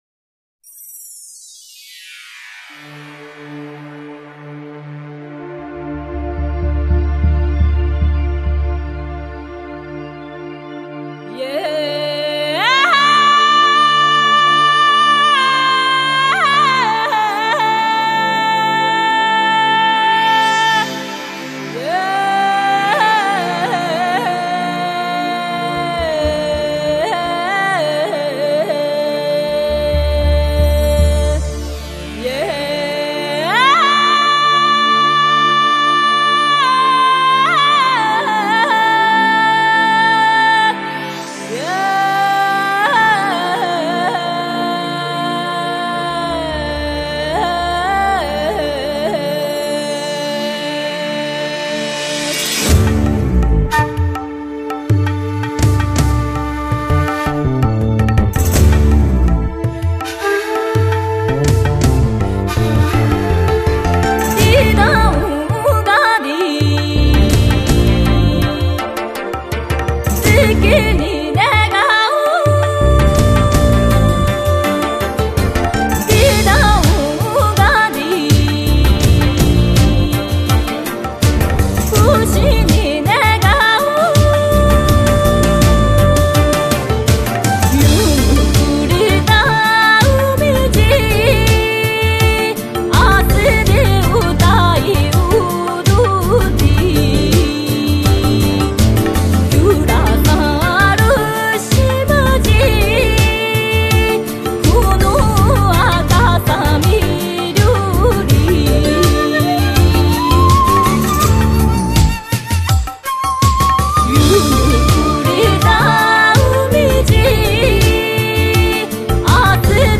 她的演唱使本身倾诉苦难的岛呗增加了一些大自然的灵气。